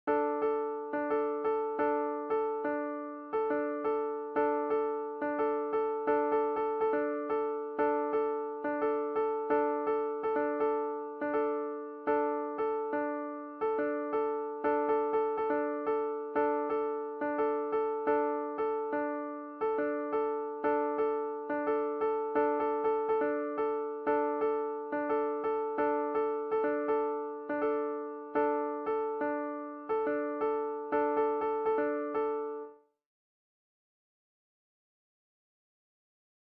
tags: Long-Hand, Look Diffic/Are Easy Sound similar but are notated differently.